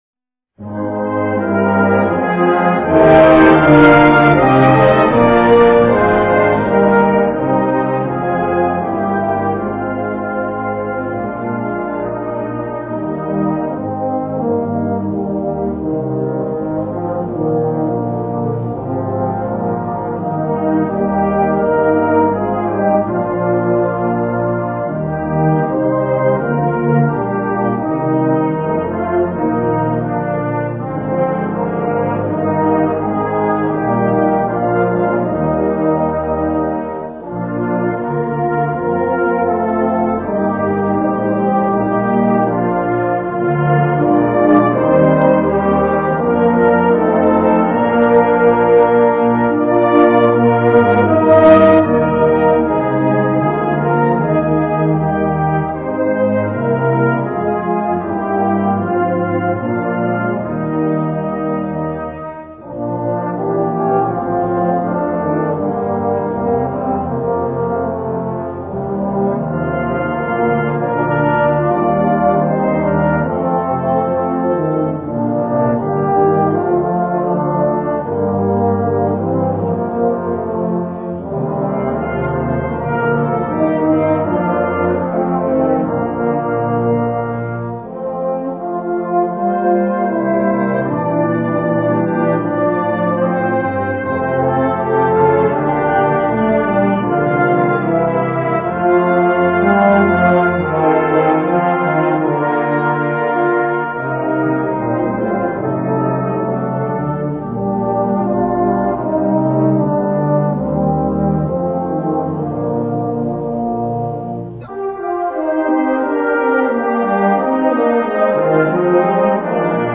Key: E♭
Tempo: 96
Metronome:     ♩ = 96